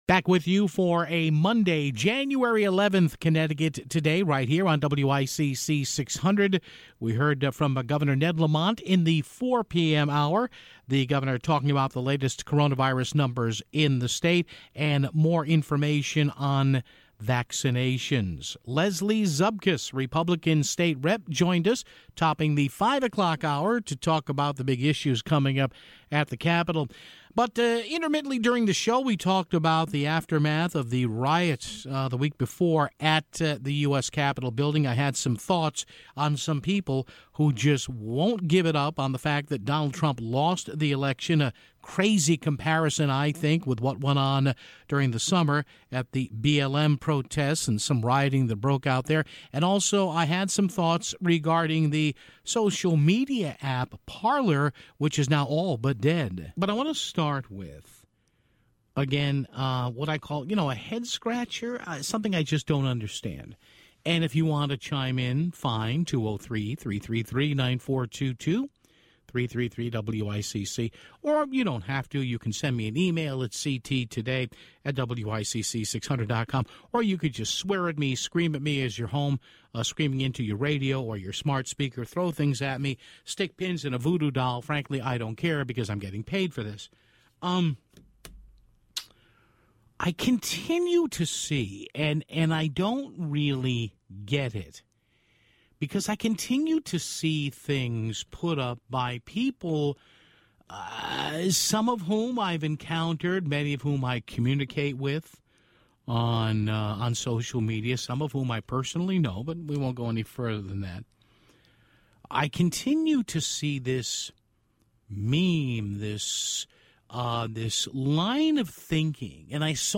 After that he would continue to speak his mind when the social media app "Parler" was taken down on various app download services(7:12). We'd provide coverage of Governor Ned Lamont's twice weekly Coronavirus meetings with the media (15:45).